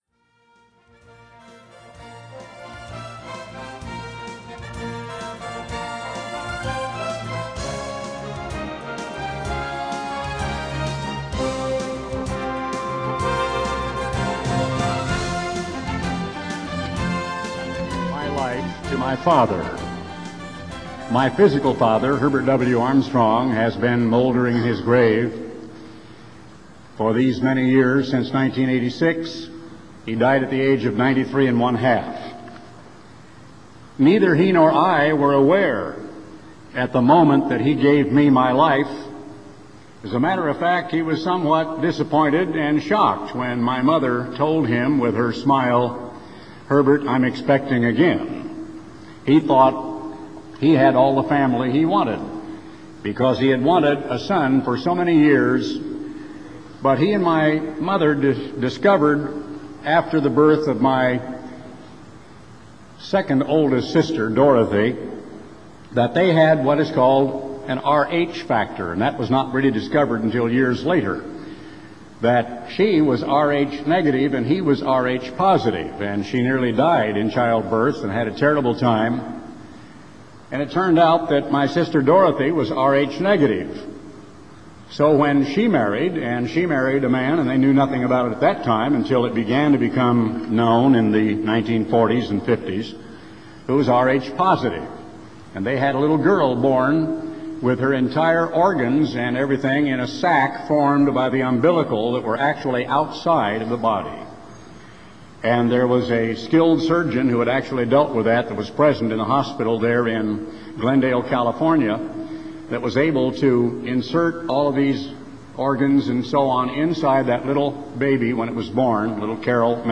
Message from Garner Ted Armstrong on June 15, 1997
Sermons given by Garner Ted Armstrong in audio format.